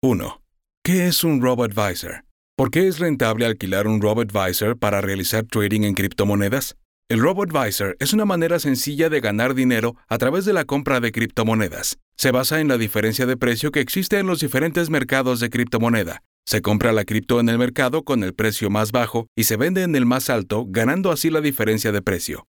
宣传片